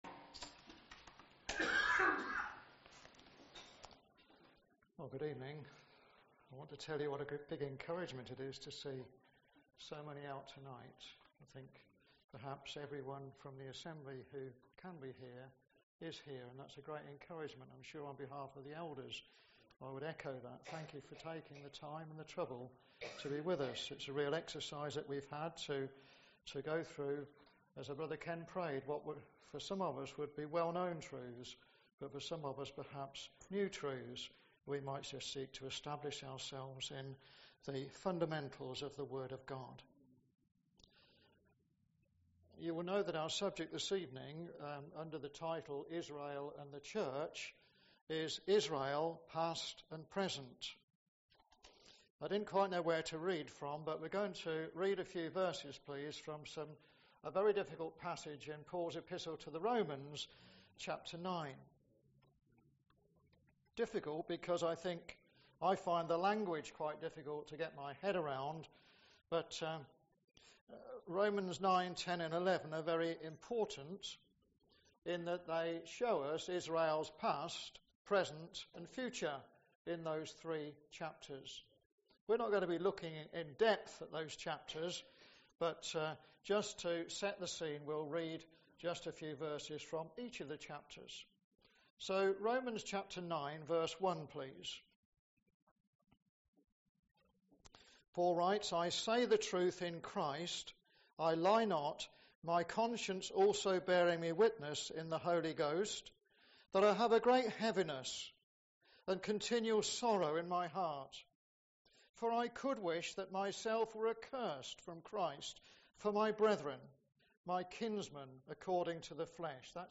11:1-8 Service Type: Ministry